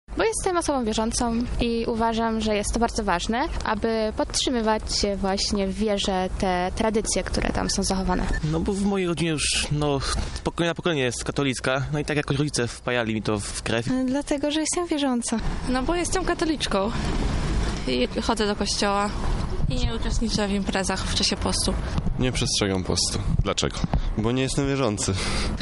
Sonda